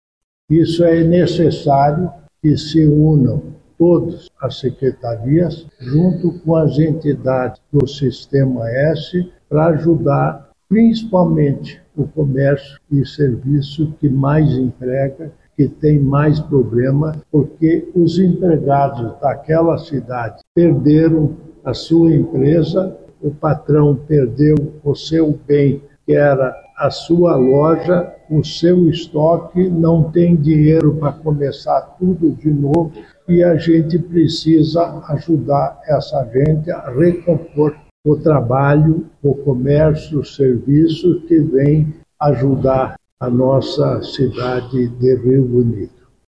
O vice-governador Darci Piana disse que o estado faz de tudo para atender os moradores da cidade e, agora, as secretarias trabalham no plano de recuperação.